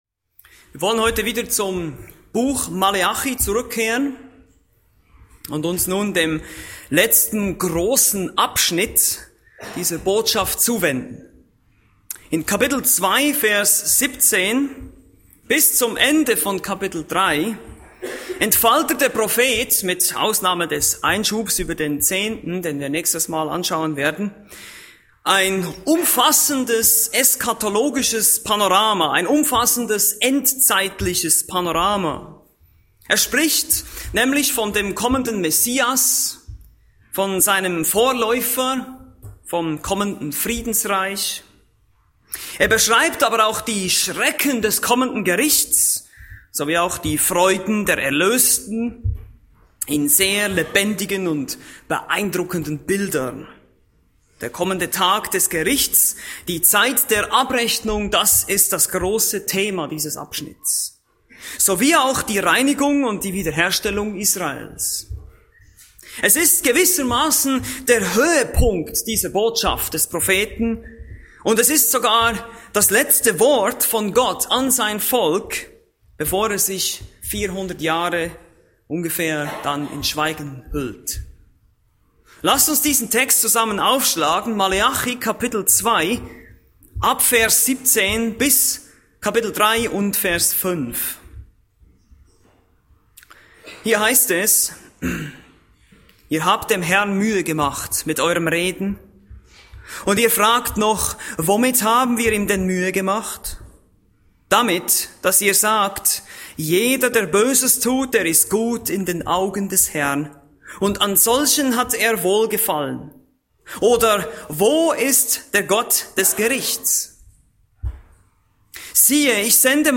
Predigten Übersicht nach Serien - Bibelgemeinde Berlin